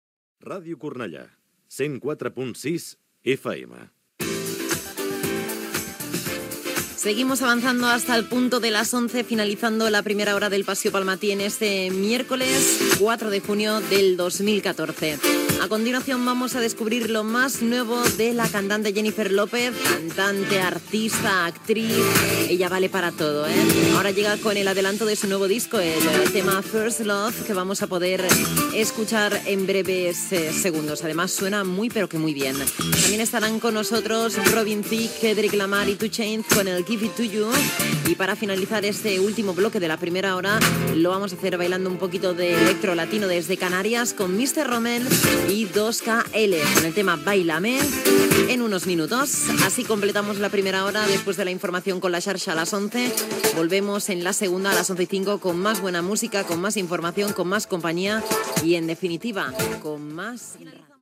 Indicatiu de la ràdio, data i presentació dels propers temes musicals
Musical
FM